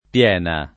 DOP: Dizionario di Ortografia e Pronunzia della lingua italiana
[ p L$ na ]